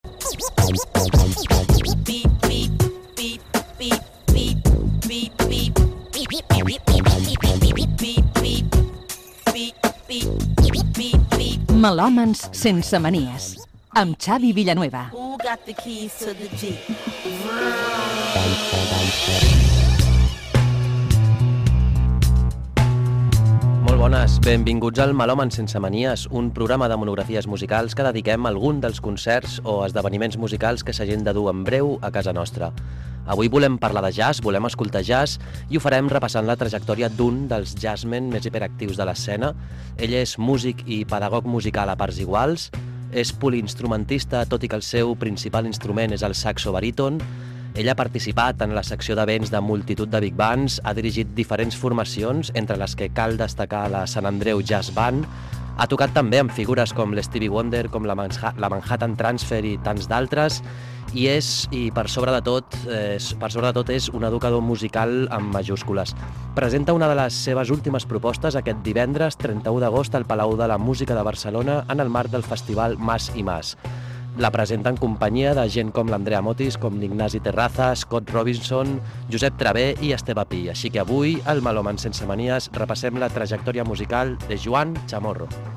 Careta, inici del programa, la trajectòria musical de Joan Chamorro.
Divulgació